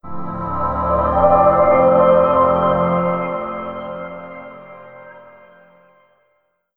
Windows NT 7.0 Beta Shutdown.wav